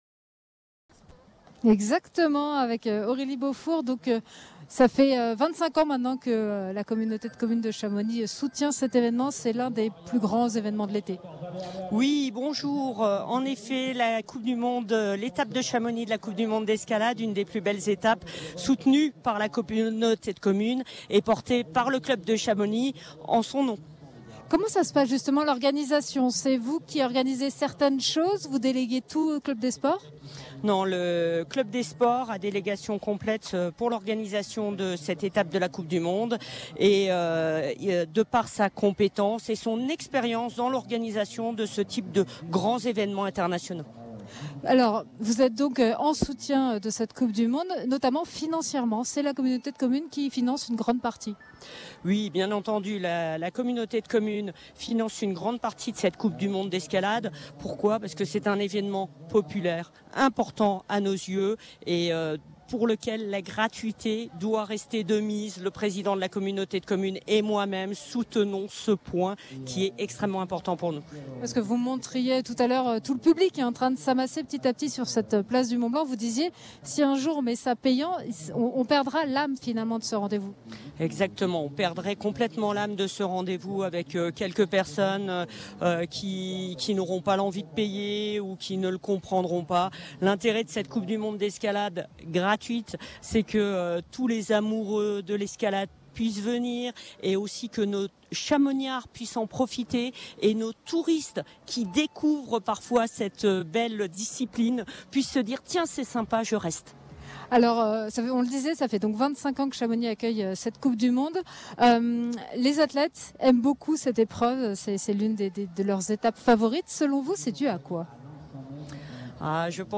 Radio Mont Blanc en direct de la Coupe du Monde d’Escalade à Chamonix !
Aurélie Beaufour, vice-président de la communauté de communes de la Vallée de Chamonix, en charge des sports.